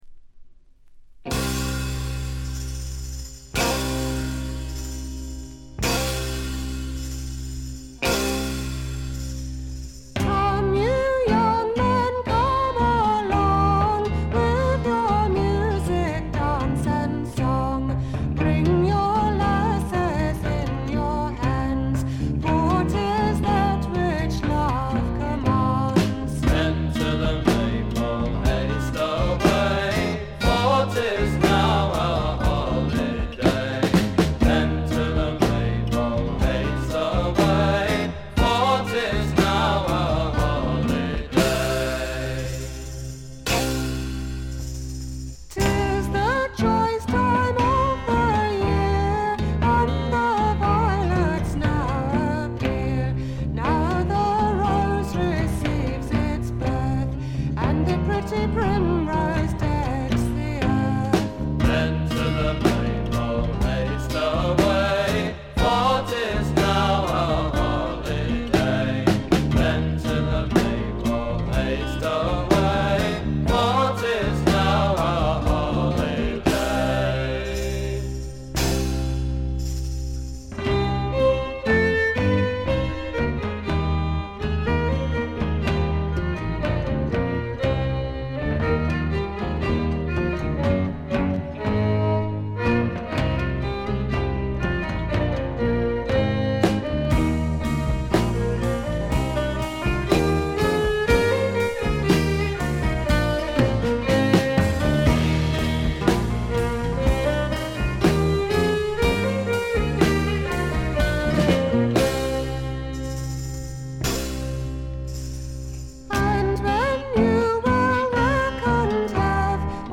静音部の軽微なチリプチ程度。
エレクトリック・トラッド基本中の基本！
試聴曲は現品からの取り込み音源です。